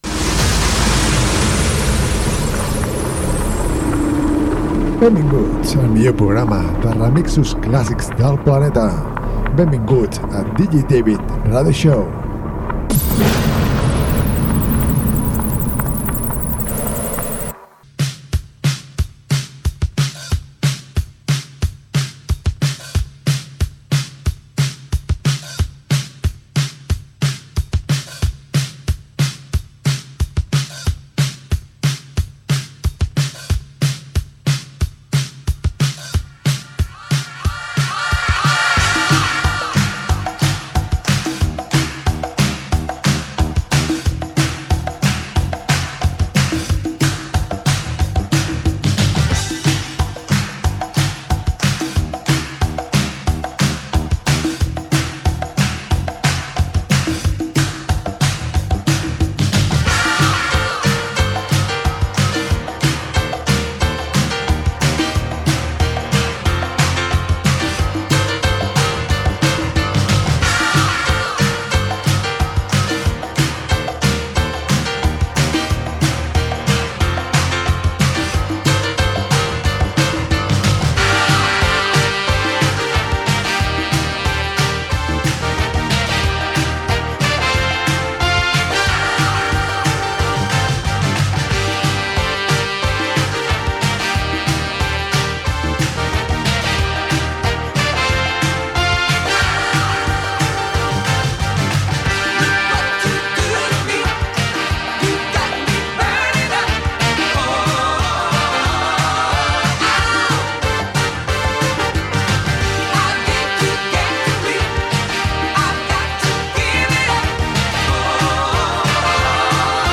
remixos classics